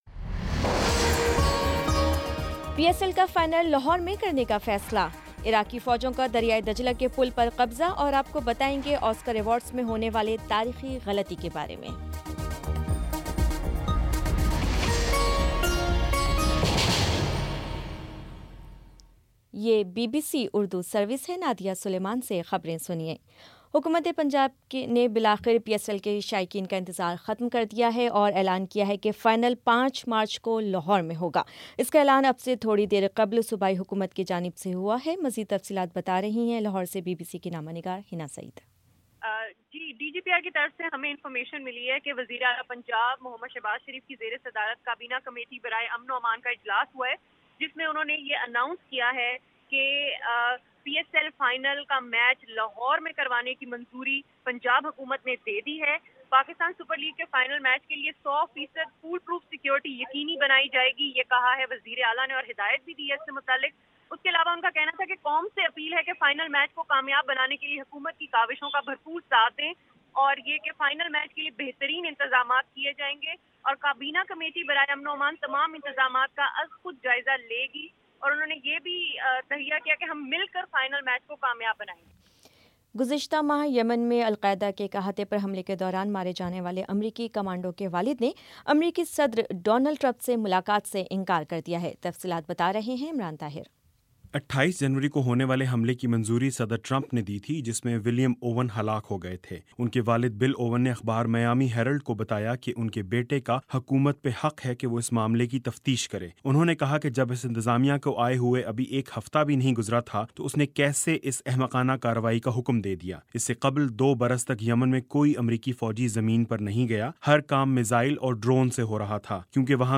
فروری 27 : شام چھ بجے کا نیوز بُلیٹن